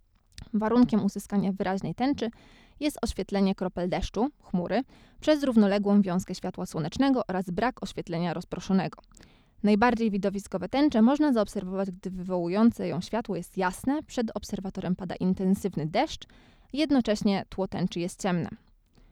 Female: Reading
Kobieta: Czytanie
ID009_reading.wav